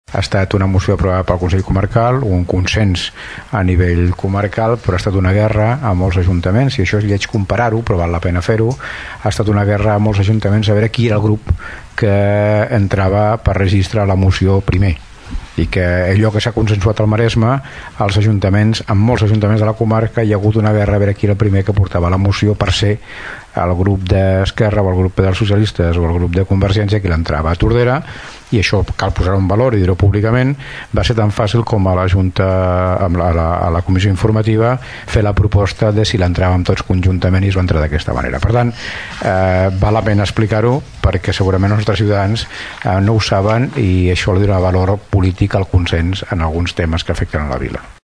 L’Alcalde de Tordera, Joan Carles Garcia, va voler posar en valor que la moció, al nostre municipi, s’hagués presentat al ple conjuntament per tots els partits que hi són representats.